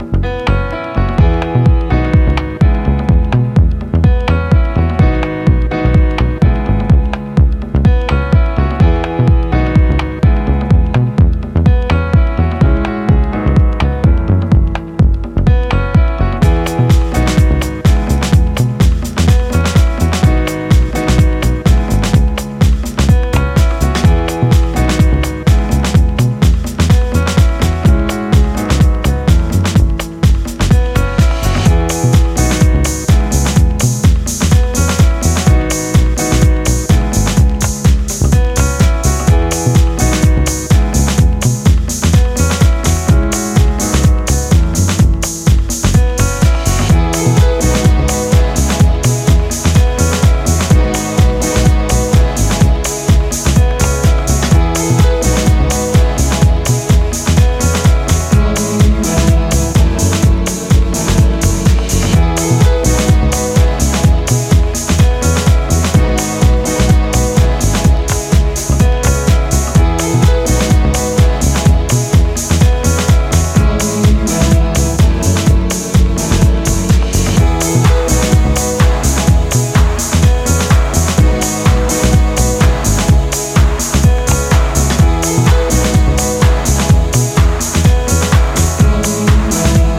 今回は、爽やかに心地良く高揚していくメロウでバレアリックなフィーリングのエレクトロニック・ハウスを展開。
ジャンル(スタイル) DEEP HOUSE